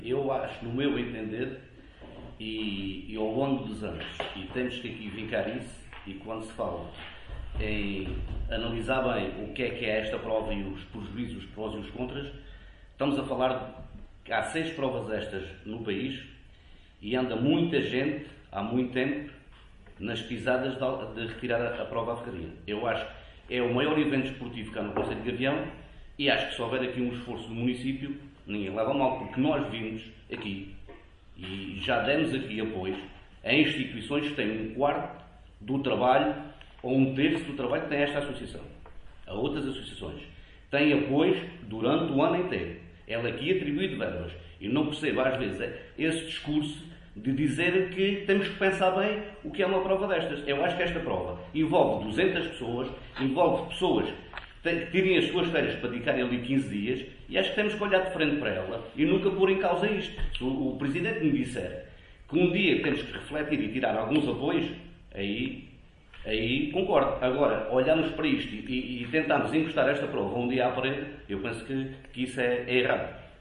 O Raid foi tema em discussão na última reunião de Câmara Municipal de Gavião.
ÁUDIO | VEREADOR DA CDU, RUI VIEIRA: